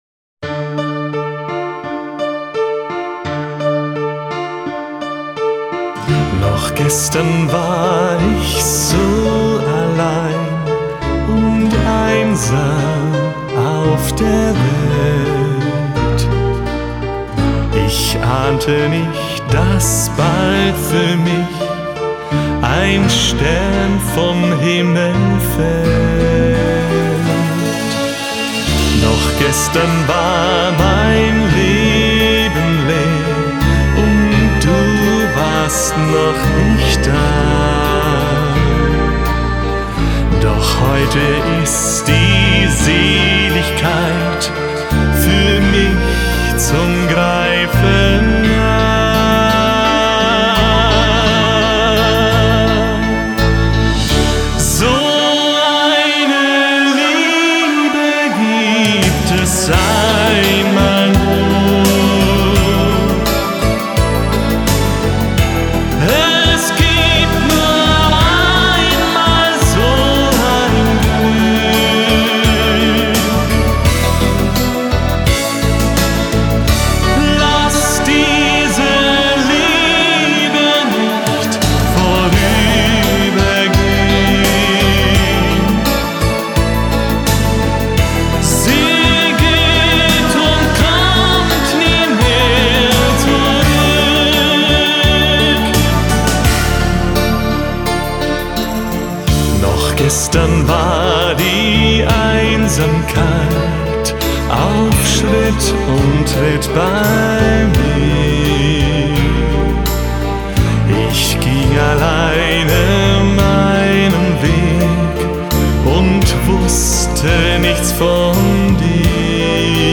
Schlager